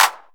Clap 1.wav